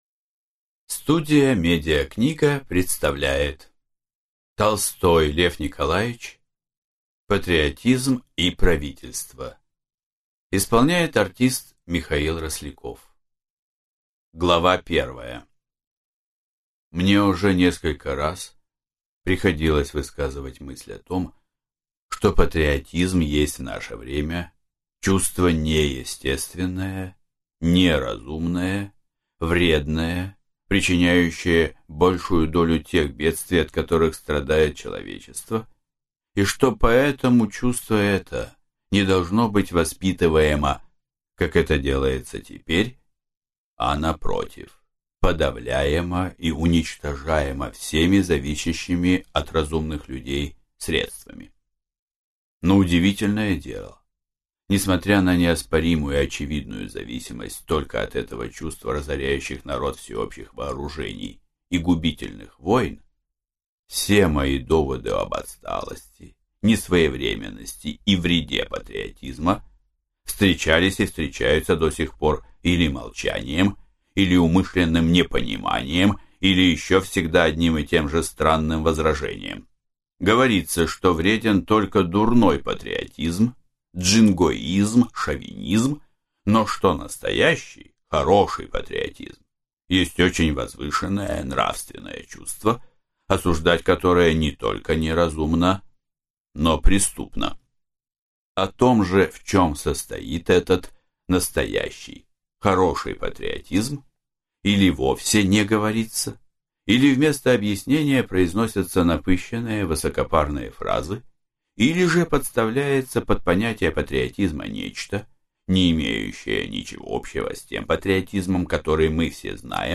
Аудиокнига Патриотизм и правительство | Библиотека аудиокниг